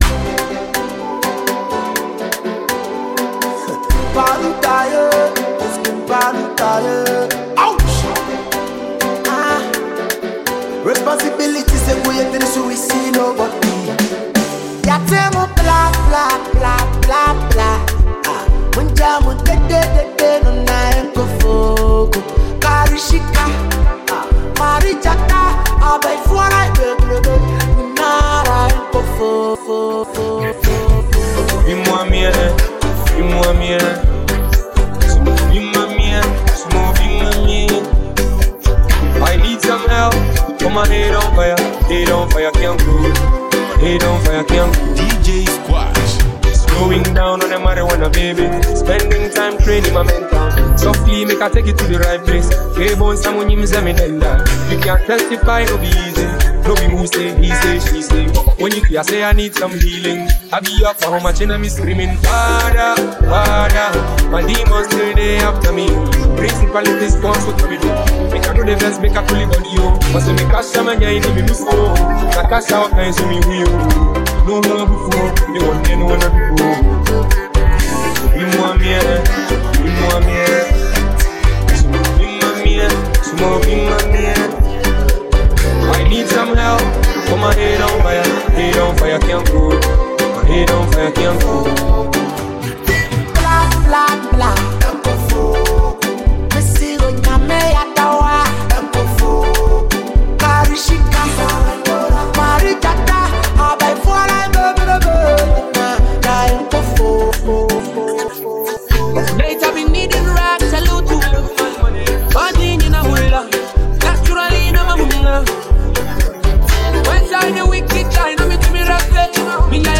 GHANA SONG NEW RELEASES
fresh and energetic mashup